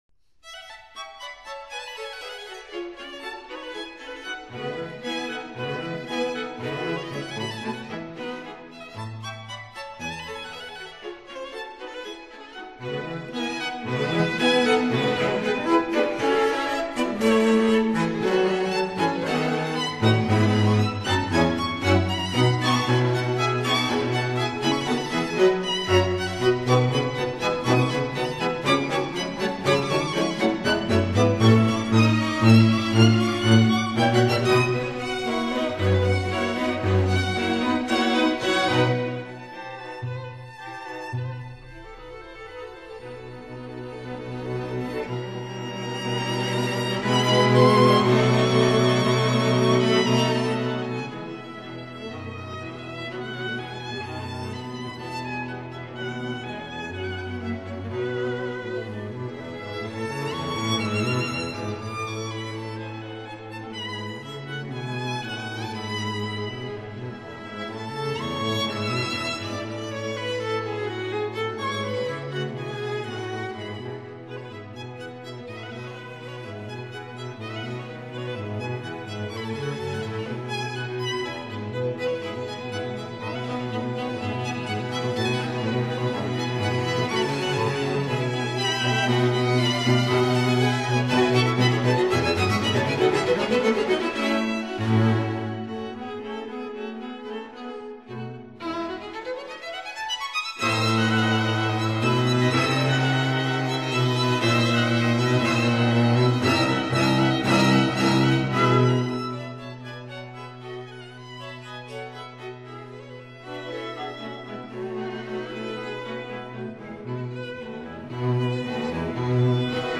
violin
cello
viola